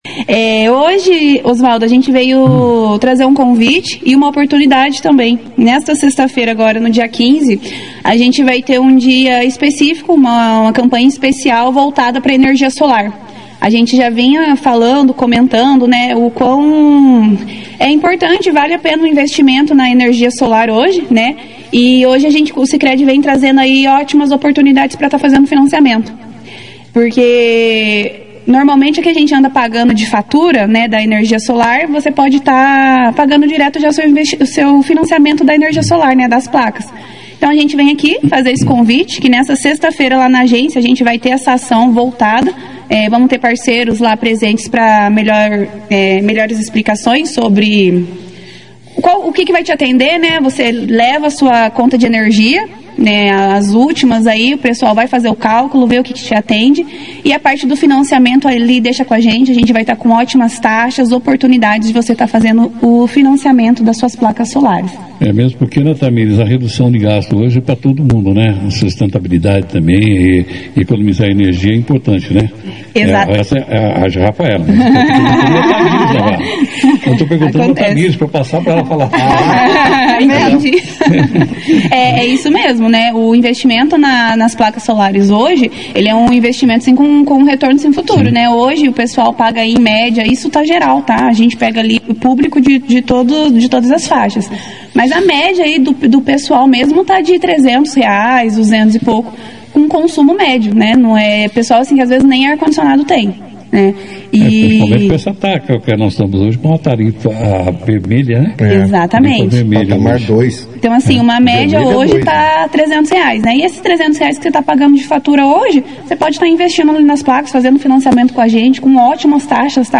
Durante a entrevista, elas explicaram as condições especiais para financiar a instalação das placas solares, tanto para residências quanto para comércios. A proposta é simples: transformar a conta de luz em um investimento, reduzindo despesas e ajudando o meio ambiente.